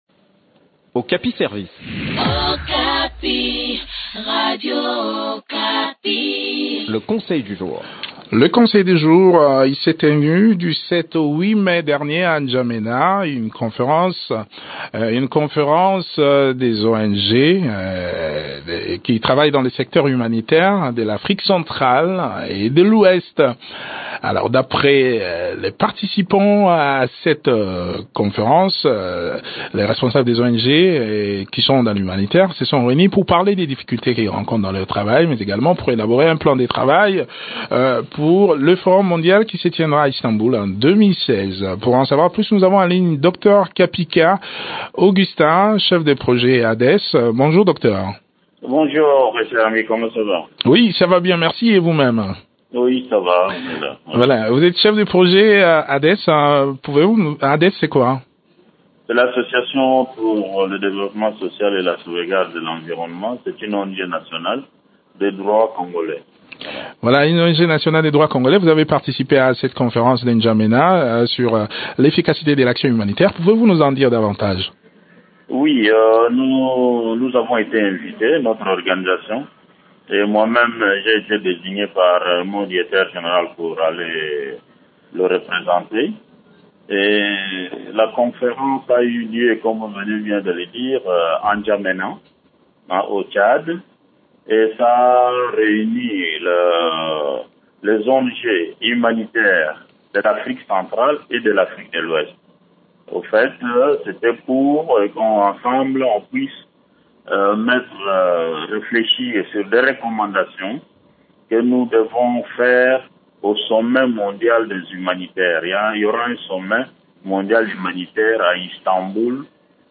Le point sur les résolutions prises au cours de cette conférence dans cet entretien